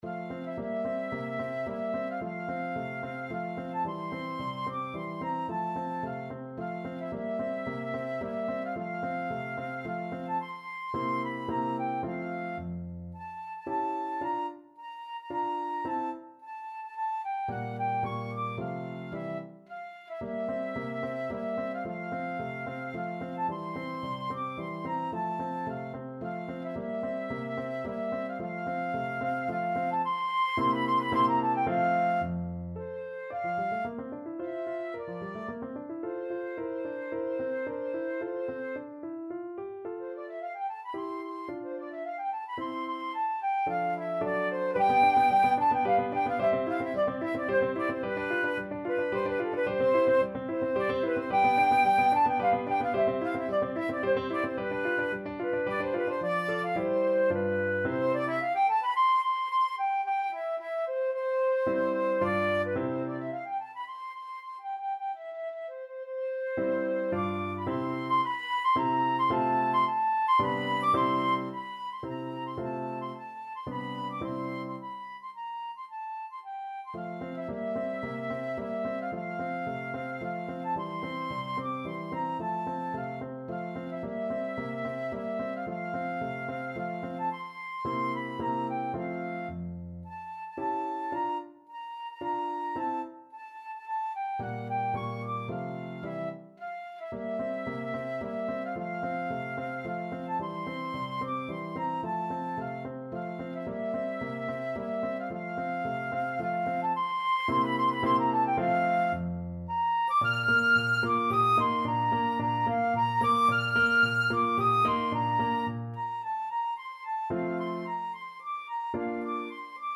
3/4 (View more 3/4 Music)
Classical (View more Classical Flute Music)